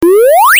cartoon22.mp3